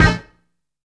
67 STAB.wav